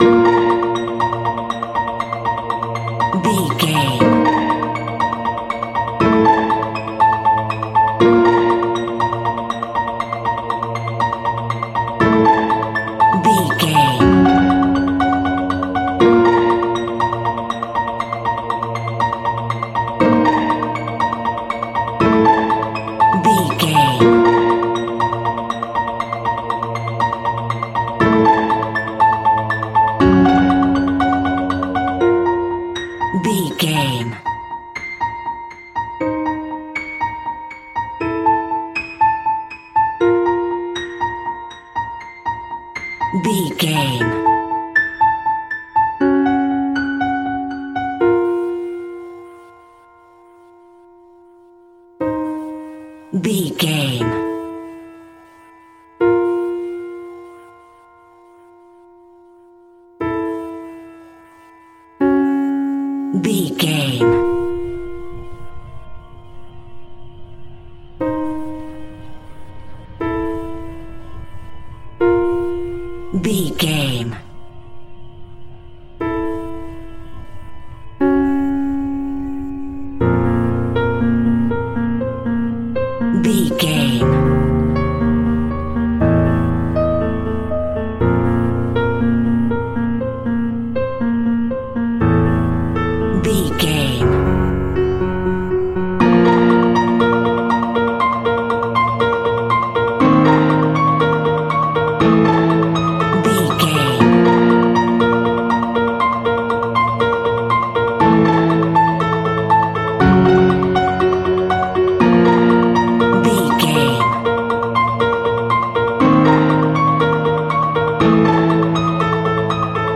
Scary Music For Halloween Night.
Aeolian/Minor
B♭
tension
ominous
dark
haunting
eerie
piano
strings
percussion
Synth Pads
atmospheres